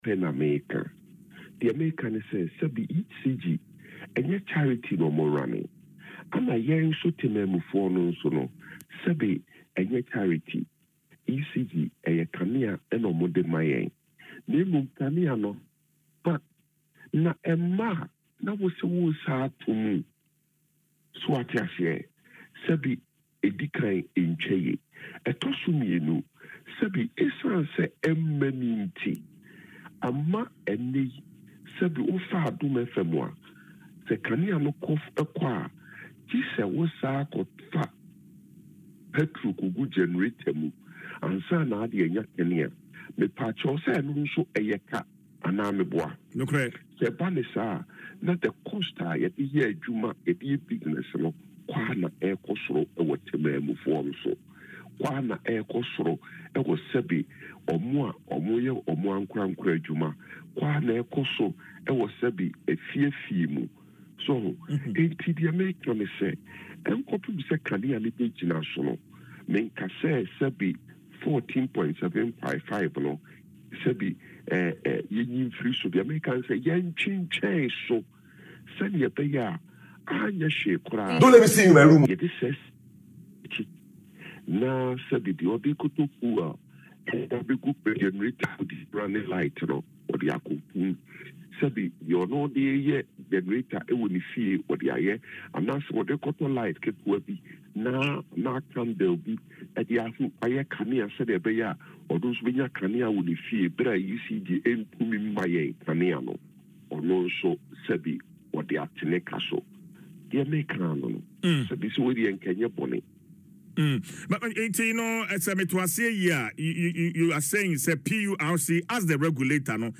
Speaking in an interview on Adom FM’s Dwaso Nsem, the MP suggested that the increment be put on hold until electricity supply is stabilised across the country.